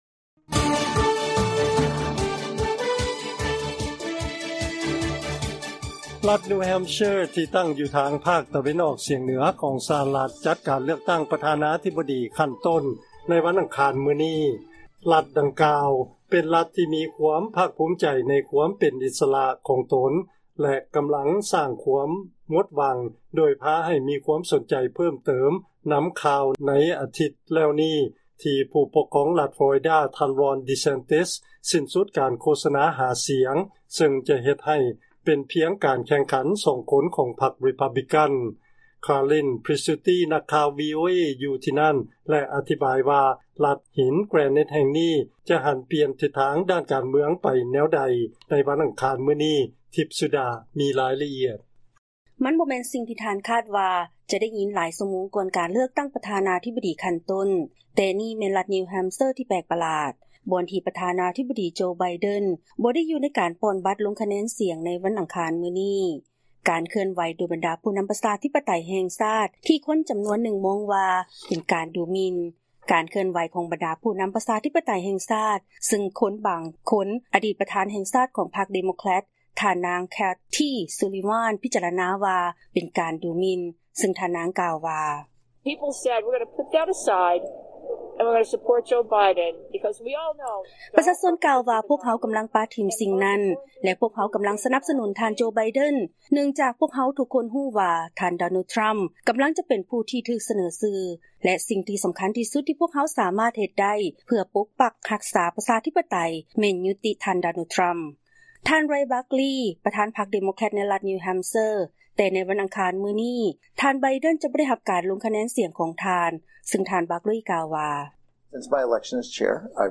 ເຊີນຟັງລາຍງານກ່ຽວກັບ ບັນດາຜູ້ລົງຄະແນນສຽງ ໃນລັດນິວແຮມເຊີ ອາດເຮັດໃຫ້ມີການປະຫຼາດໃຈໃນການເລືອກຕັ້ງຂັ້ນຕົ້ນ ໃນວັນອັງຄານນີ້